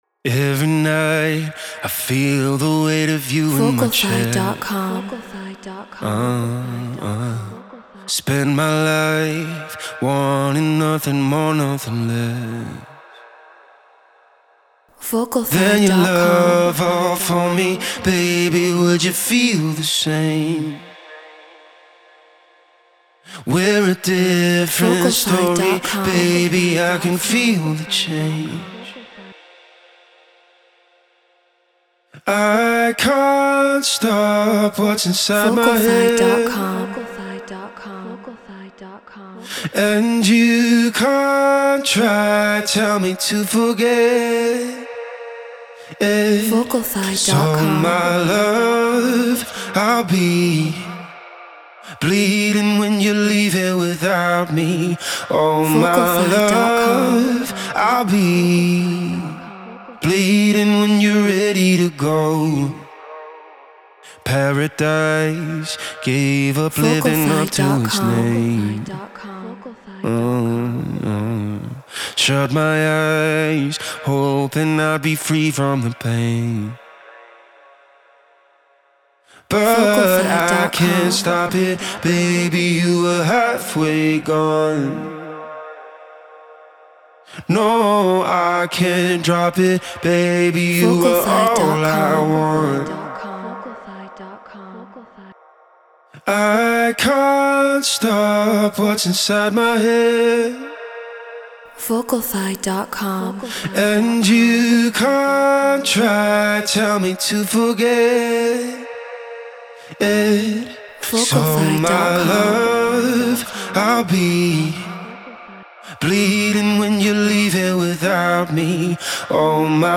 House 120 BPM D#min
Treated Room